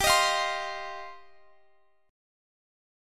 Listen to G7sus4#5 strummed